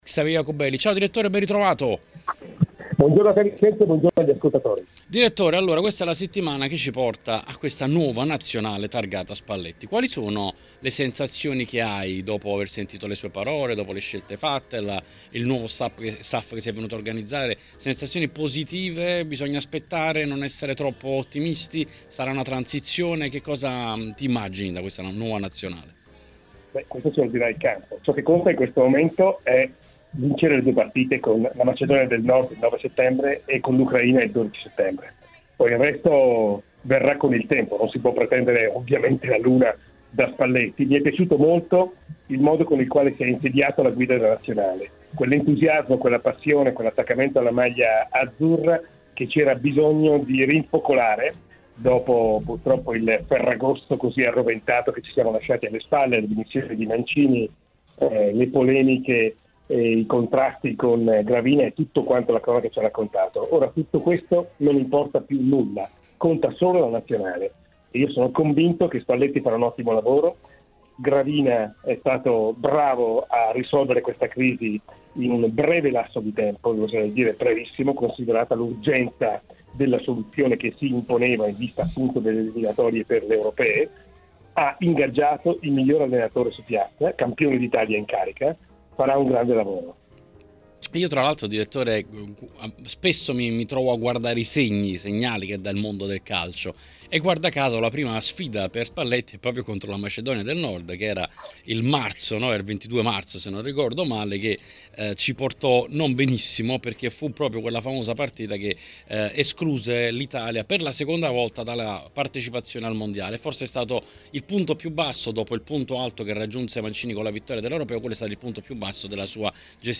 Durante l'appuntamento odierno con L’Editoriale è intervenuto sulle frequenze di TMW Radio Xavier Jacobelli.